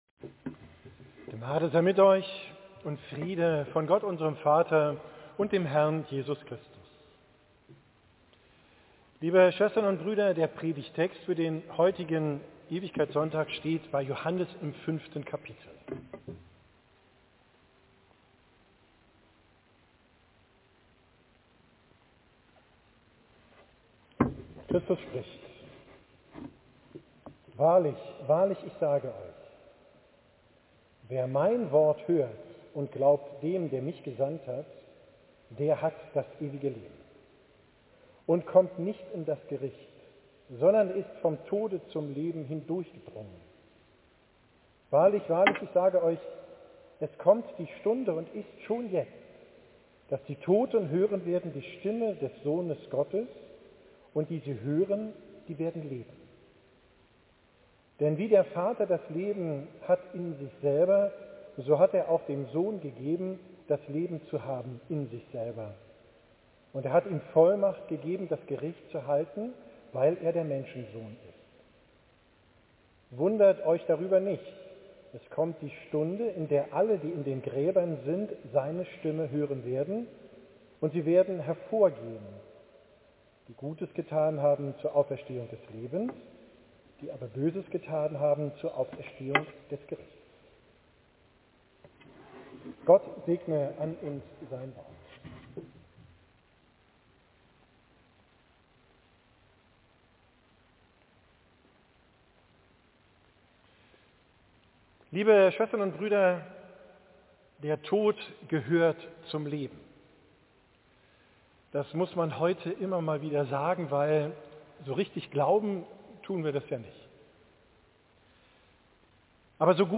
Predigt zum Ewigkeitssonntag, 23.